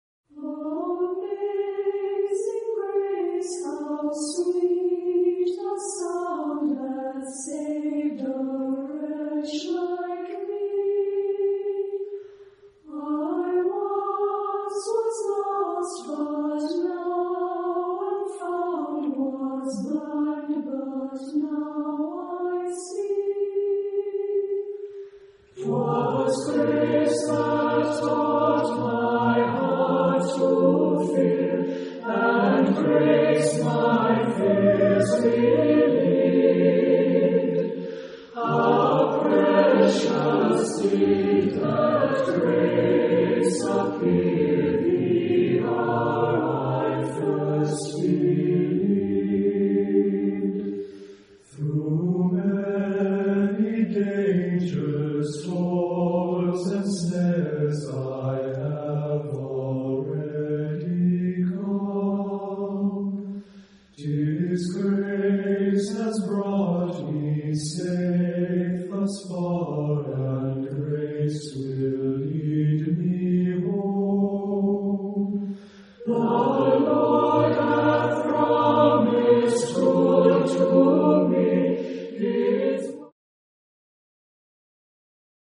SATB (4 voices mixed) ; Full score.
Hymntune setting. Hymn (sacred).
Genre-Style-Form: Sacred ; Popular ; Hymntune setting ; Hymn (sacred) Mood of the piece: tender ; prayerful Type of Choir: SATB (4 mixed voices )
Tonality: D aeolian